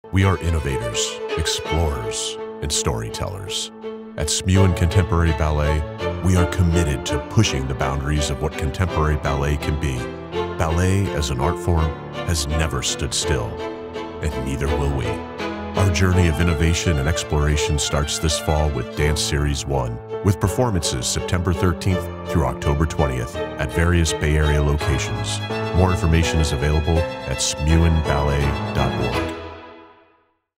Company Promo
English (North American)
Middle Aged
- Friendly, warm and engaging
- Sennheiser MKH416 microphone, Adobe Audition, RX10